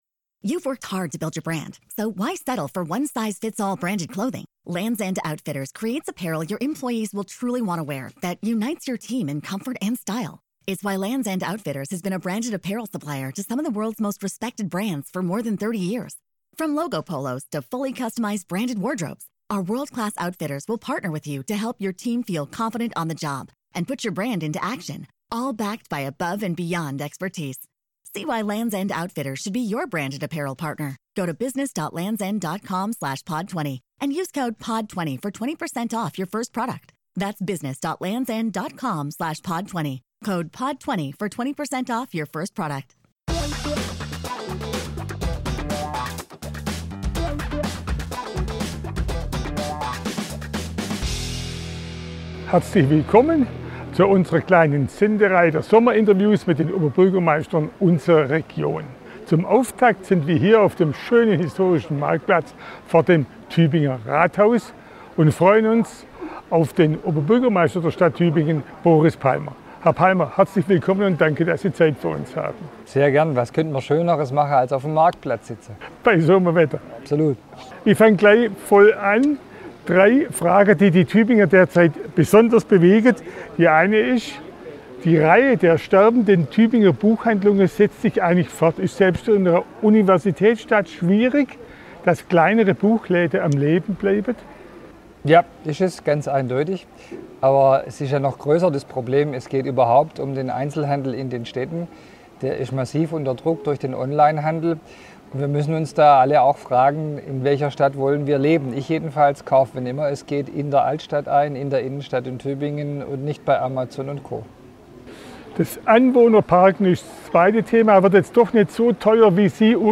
Boris Palmer im Gespräch - OB von Tübingen im Sommerinterview ~ RTF1 Neckar-Alb Podcast | Reutlingen Tübingen Zollernalb Podcast
Unser Sommerinterview mit Boris Palmer (Grüne), Oberbürgermeister von Tübingen.
Boris-Palmer-Oberbuergermeister-Tuebingen-Sommerinterview-2021.mp3